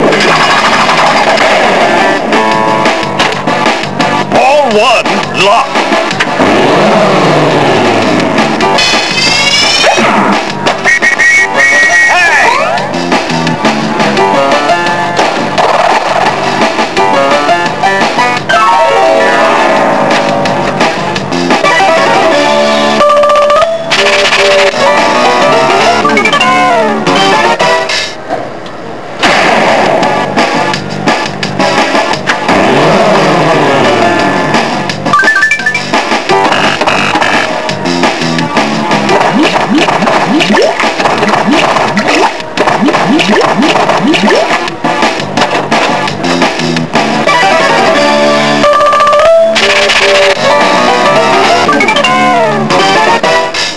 Download 583Kb Tema fra spillet